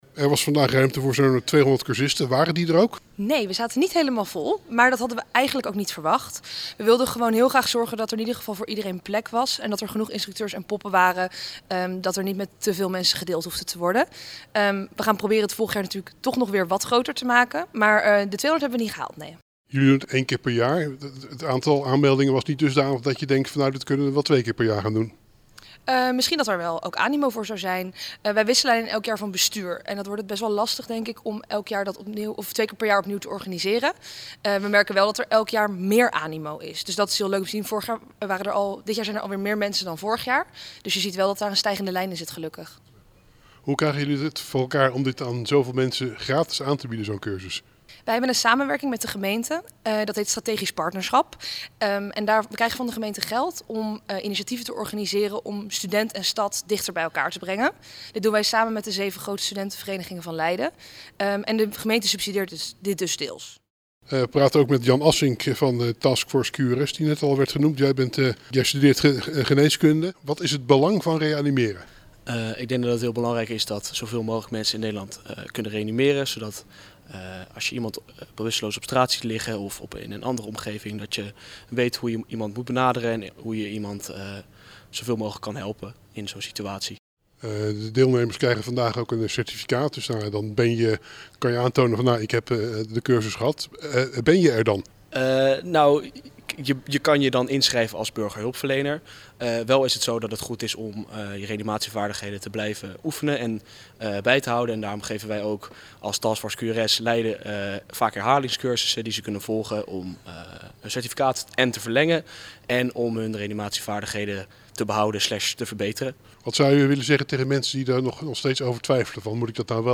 in gesprek
Gesprekken-Hart-voor-de-Stad.mp3